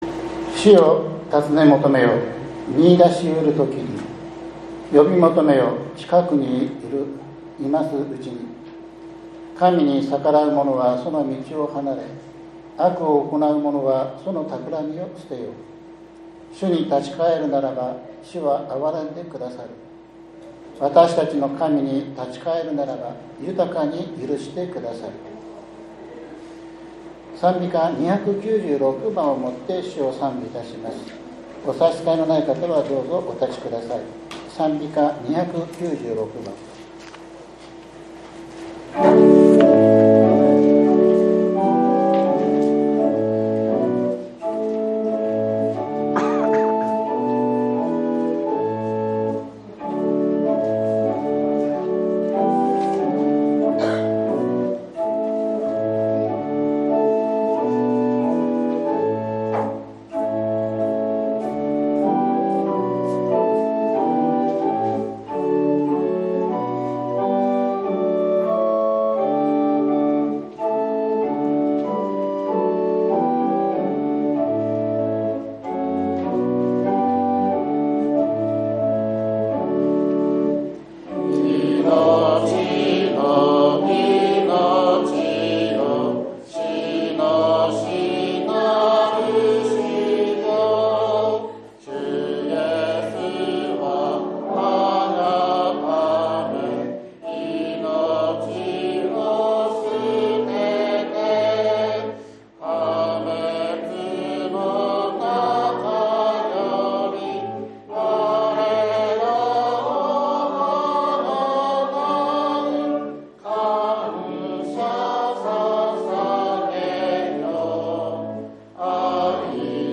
３月１日（日）主日礼拝